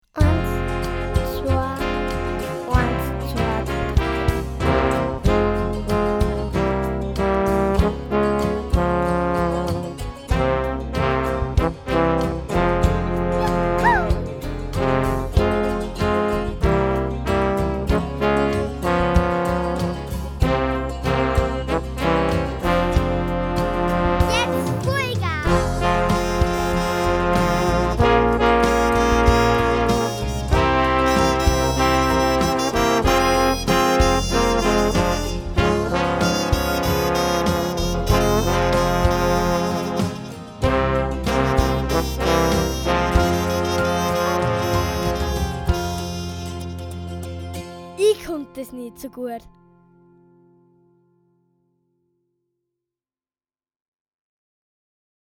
Besetzung: Posaune